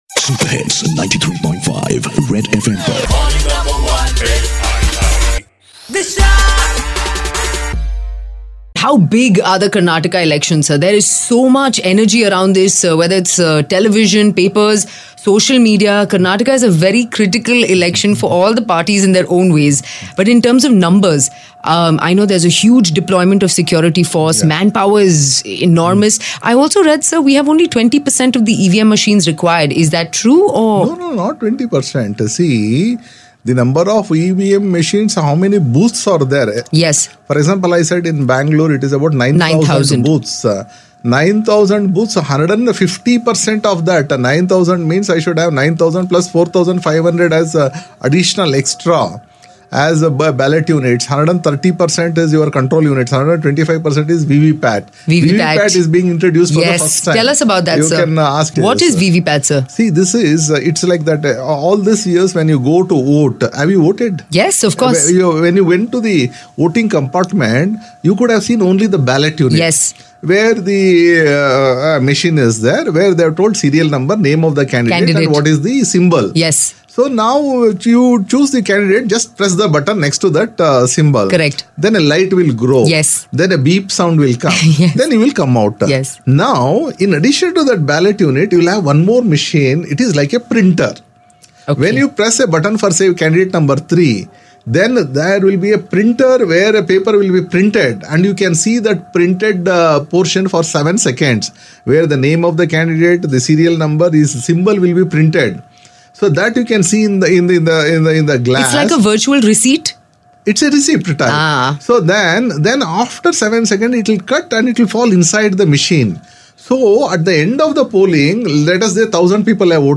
VVPAT explained by BBMP Commissioner